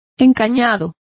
Complete with pronunciation of the translation of conduit.